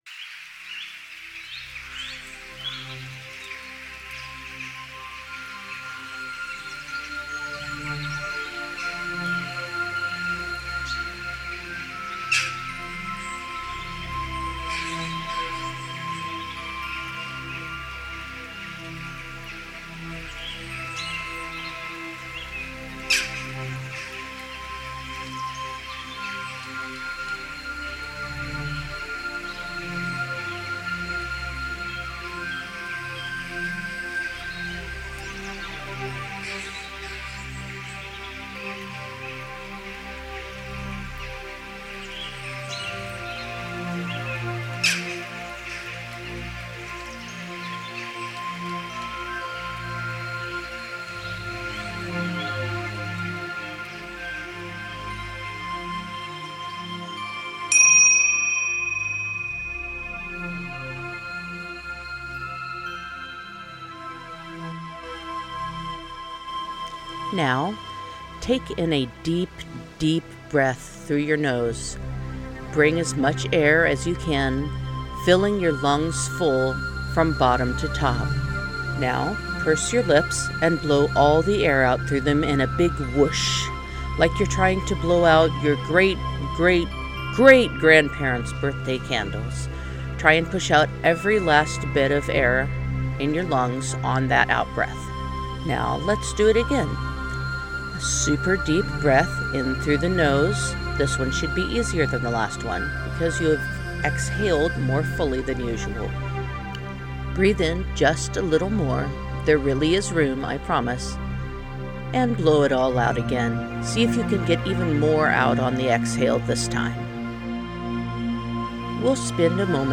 no intro version.
The guided meditation track uses a chime to begin this meditation, as do most of the meditations in this series.
GuidedMeditation-cleansingbreath-no-intro.mp3